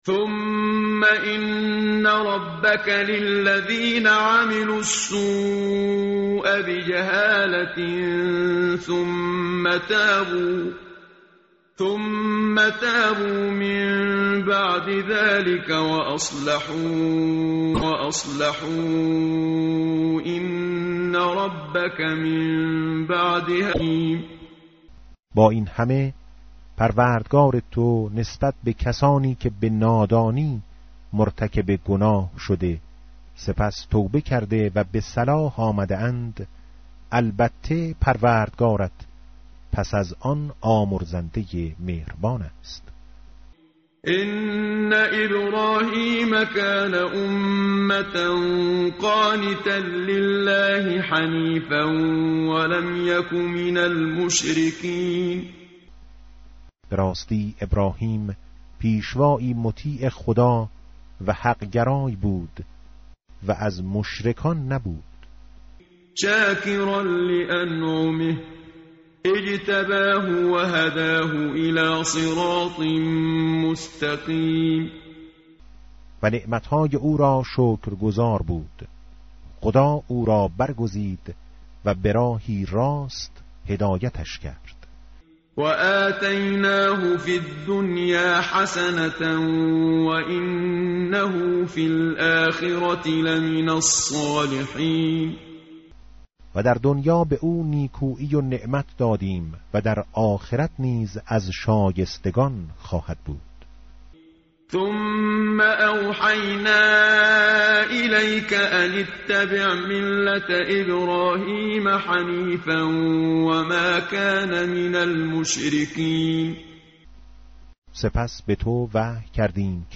tartil_menshavi va tarjome_Page_281.mp3